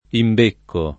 DOP: Dizionario di Ortografia e Pronunzia della lingua italiana
imbeccare